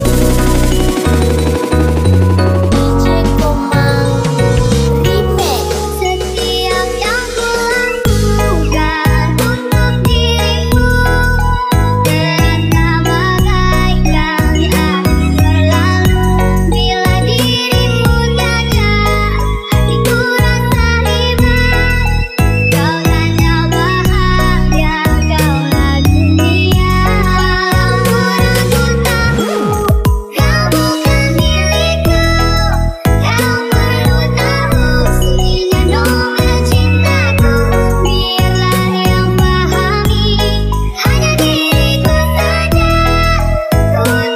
SLOW BASS